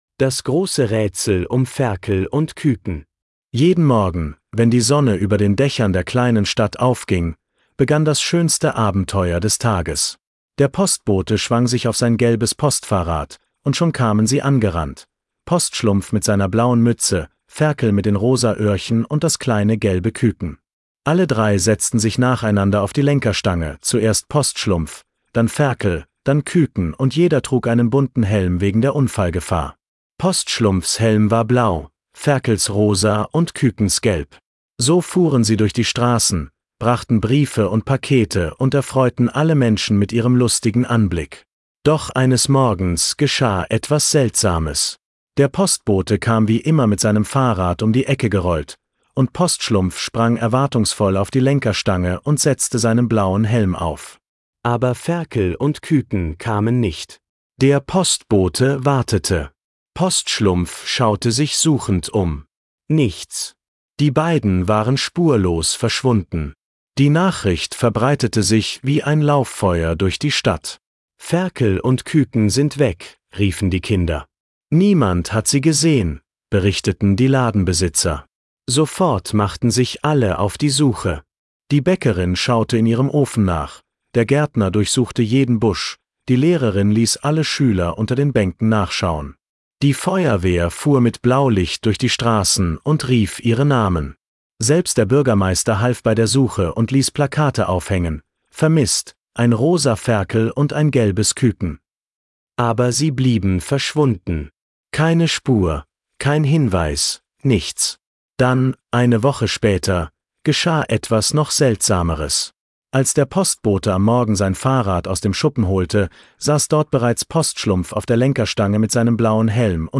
Die Geschichte mit Postschlumpf, Ferkel und Küken gibt es jetzt auch vorgelesen.